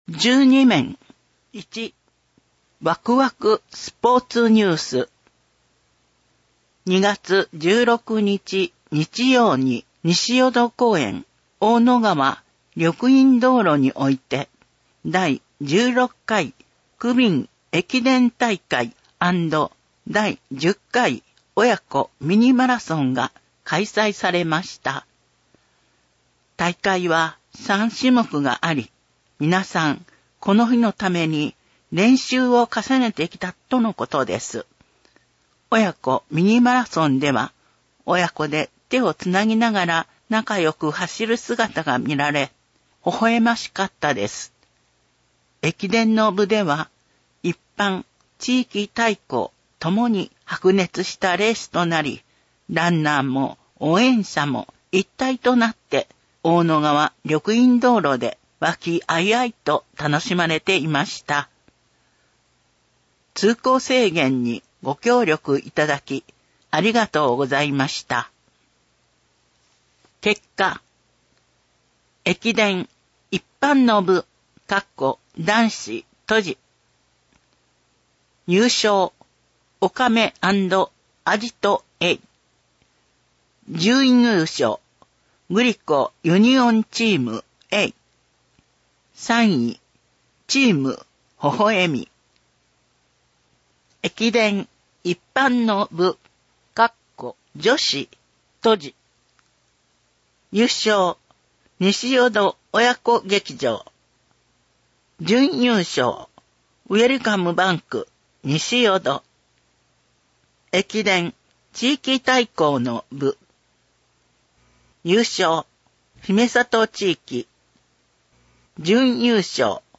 西淀川区ボランティア・市民活動センター登録の音訳グループ「やまびこ」さんのご協力により音訳CD版（デイジー図書）が毎月発行されています。